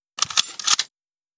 Reload Sniper.wav